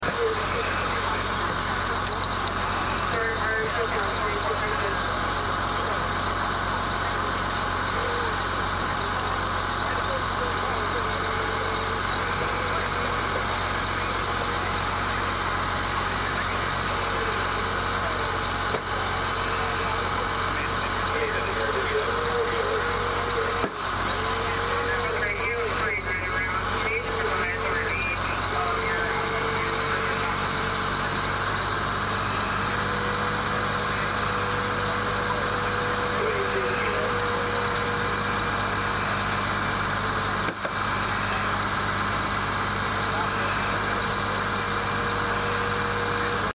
Ridge and Bay District Volunteers Extricate Victim of a car crash on 4th of July evening in St. James, MD while Lexington Park VRS members tend to the trapped individual.